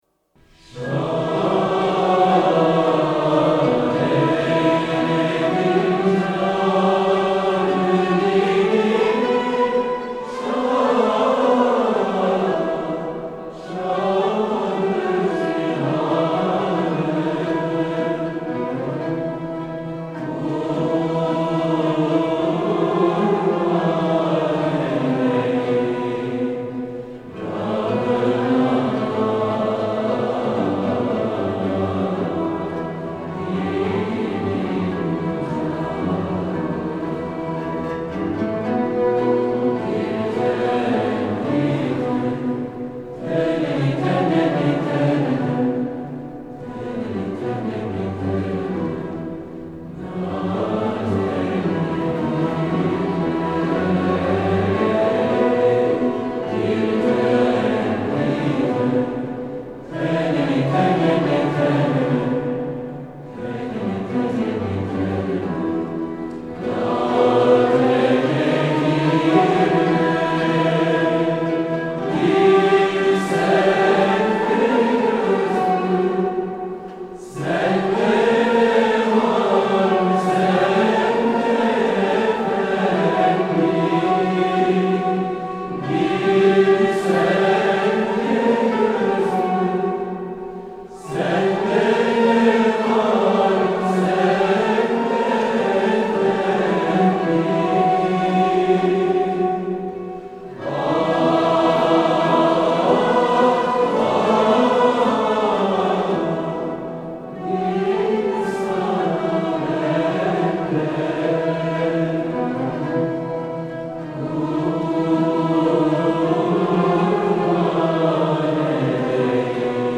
Şad Eyledi Can Ü Dilimi Şah-i Cihanım(Ruh-i Revanım) - Dede Efendi - Sultani Yegah
Makam: Sultani Yegah
Usûl: Yürük Semai